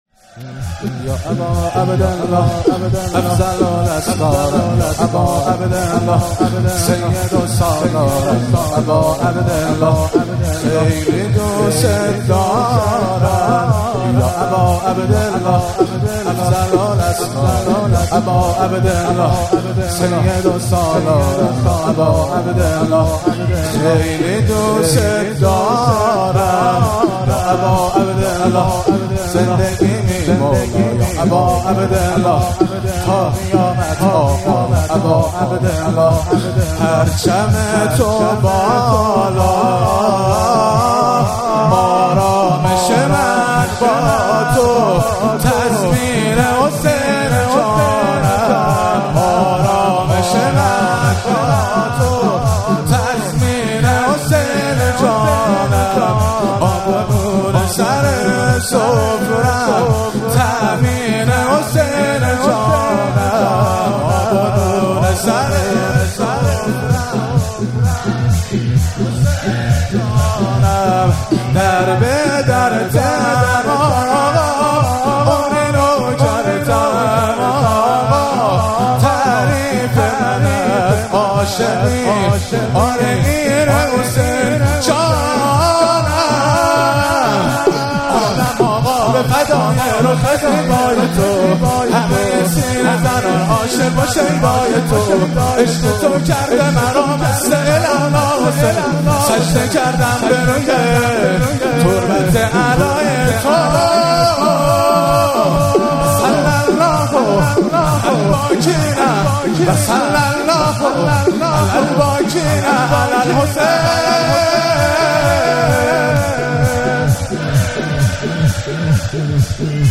مراسم مناجات خوانی و احیای شب بیست و یکم و عزاداری شهادت حضرت امیرالمومنین علی علیه السلام ماه رمضان 1444